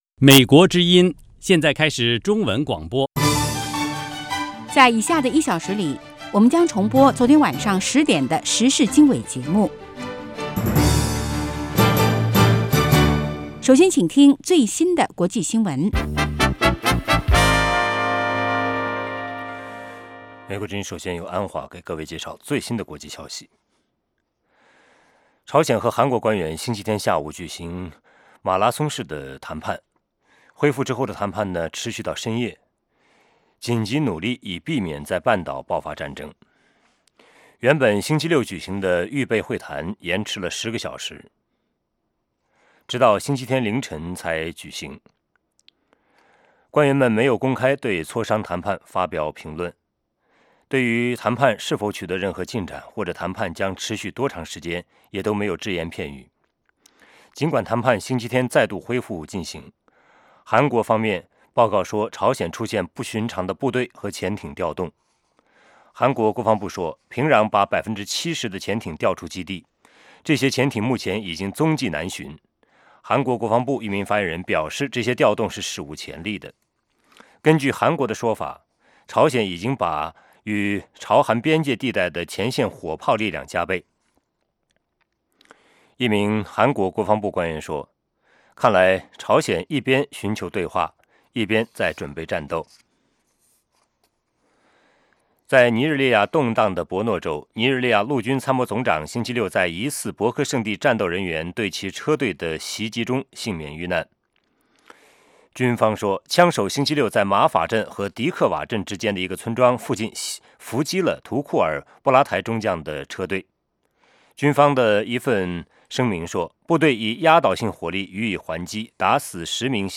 北京时间早上6-7点广播节目 这个小时我们播报最新国际新闻，并重播前一天晚上10-11点的时事经纬节目。